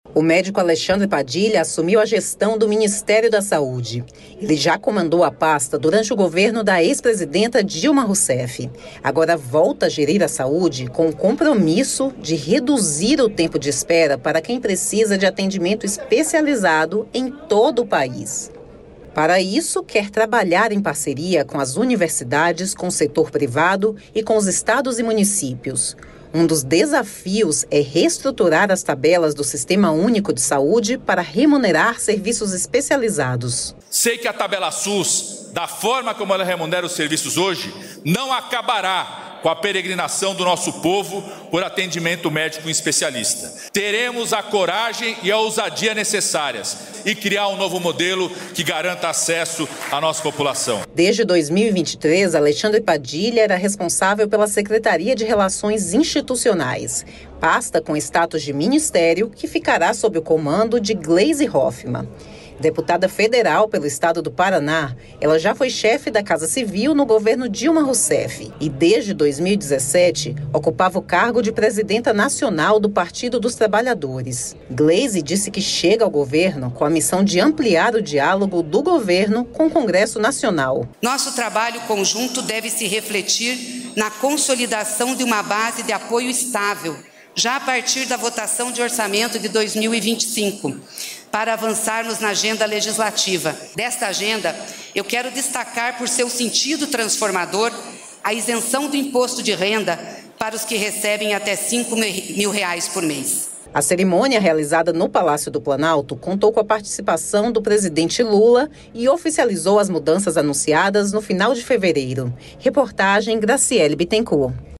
Matérias da Voz Goiás ganha trecho do BRT e investimentos para três Institutos Federais O estado de Goiás vai receber investimentos do Programa de Aceleração do Crescimento nas áreas de mobilidade e educação.